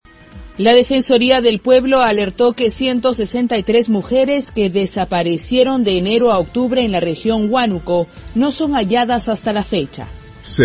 Titulares